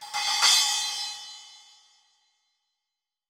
Cymbal OS 07.wav